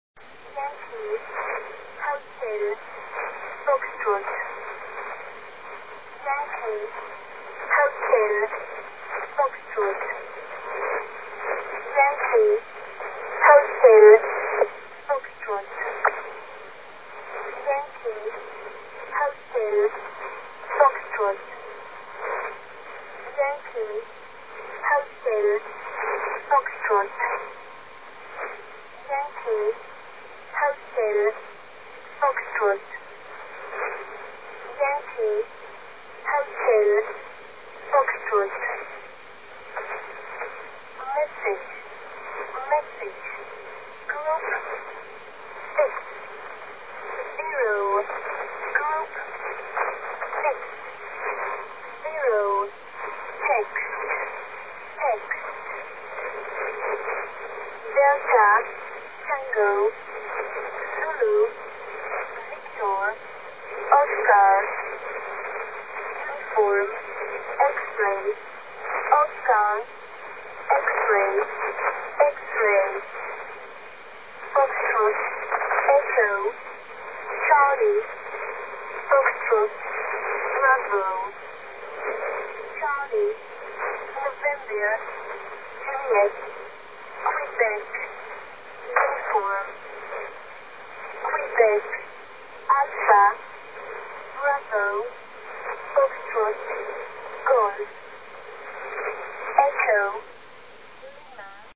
Und bei 5820,0 kHz treffe ich auf ein sehr lautes Signal. Aus dem Kopfhörer treffen immer wieder drei Buchstaben im NATO-Alphabet auf meine Ohren: YANKEE HOTEL FOXTROTT.
Zudem ist die verwendete Frauenstimme eine völlig andere:
Die vorliegende Nachricht umfasst 60 Gruppen, die alle zunächst einmal vorgetragen werden.